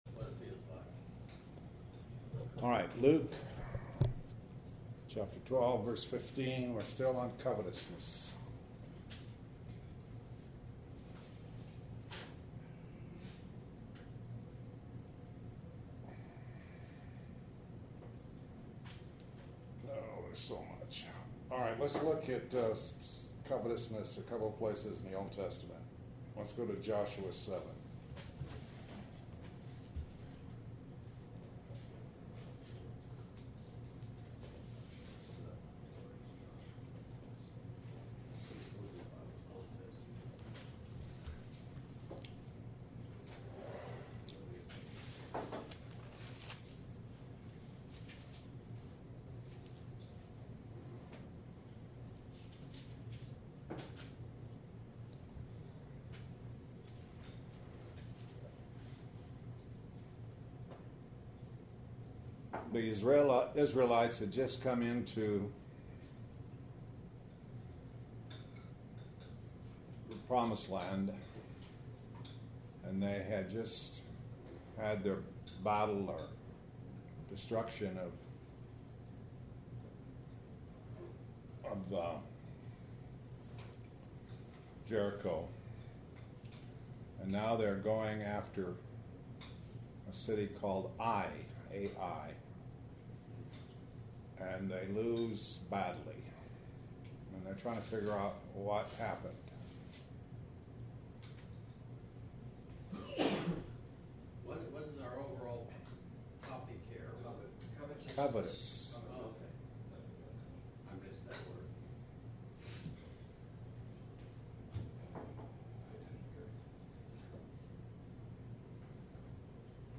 Dec. 17, 2013 – Luke 12:15 Posted on February 25, 2014 by admin Dec. 17, 2013 – Luke 12:15 Covetousness Joshua 7:5-26, II Kings 5:20 Acts 5:1-11, I Timothy 6:1-11 This entry was posted in Morning Bible Studies .